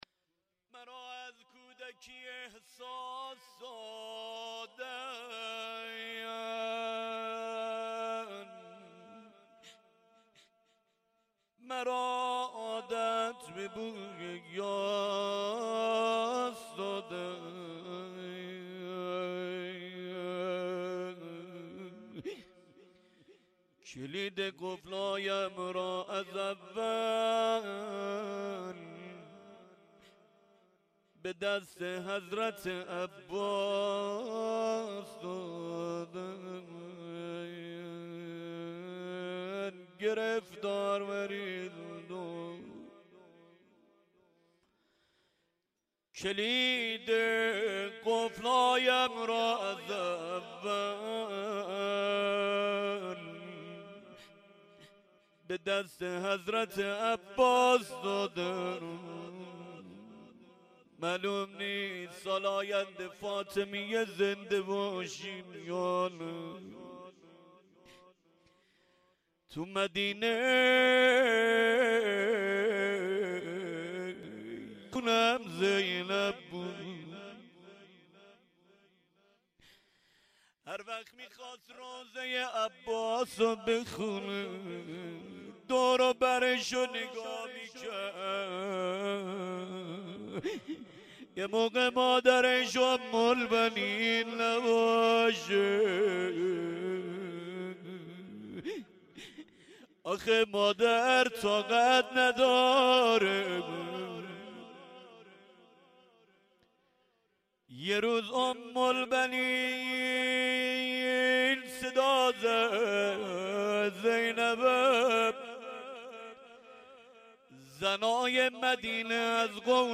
شب سوم فاطمیه اول(سلام الله علیها)1397
روضه آخر1
فاطمیه-اول97-شب3-روضه-آخر1.mp3